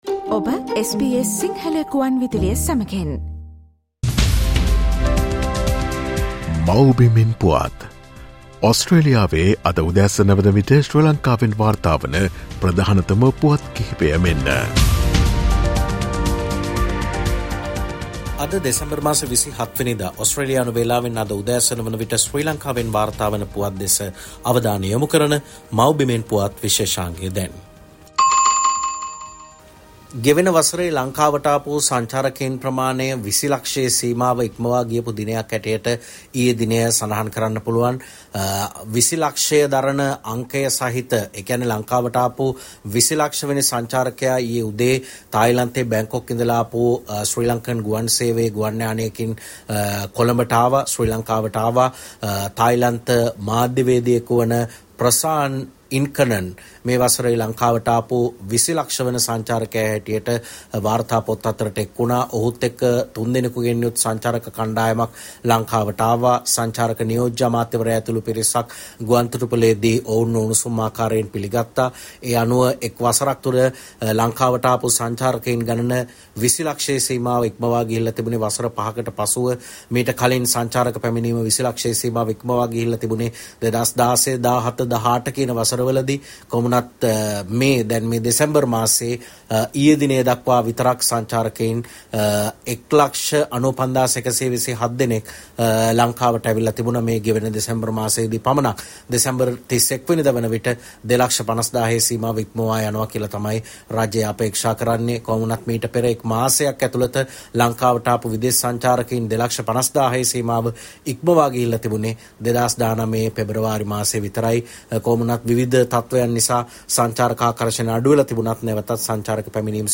SBS Sinhala featuring the latest news reported from Sri Lanka